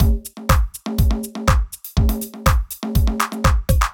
ORG Beat - Mix 6.wav